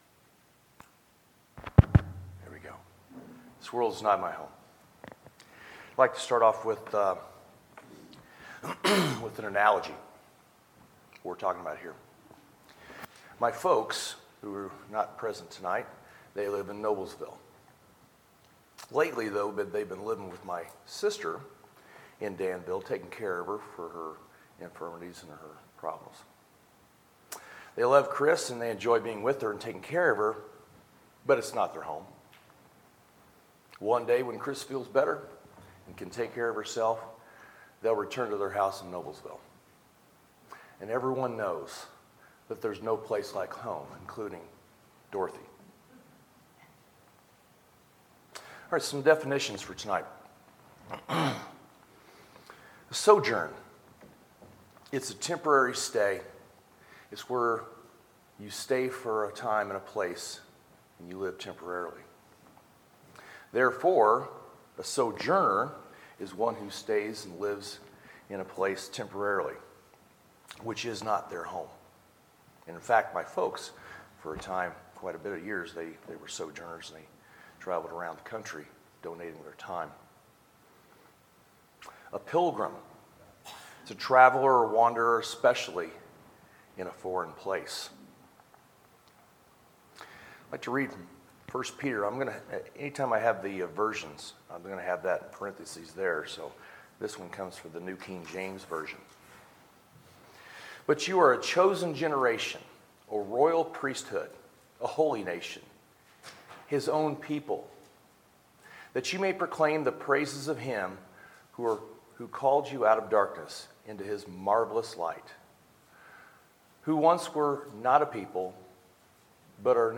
Sermons, February 11, 2018